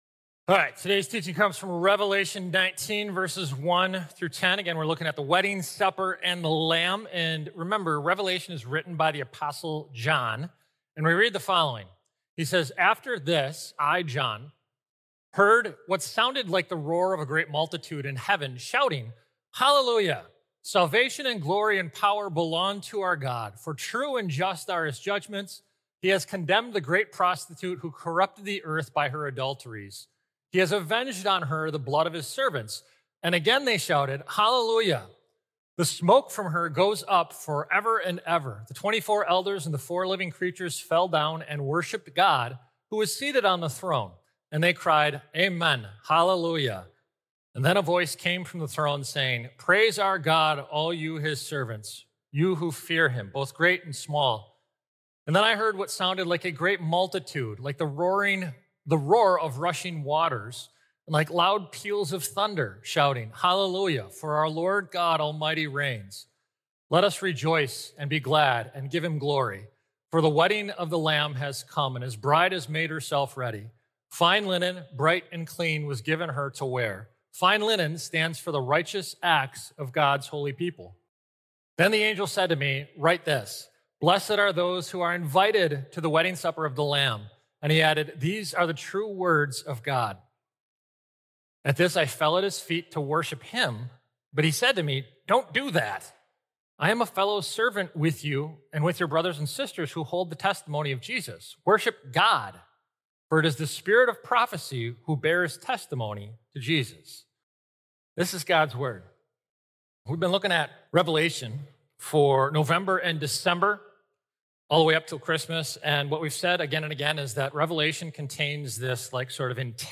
Weekly Sermons from St. Marcus Lutheran Church, Milwaukee, Wisconsin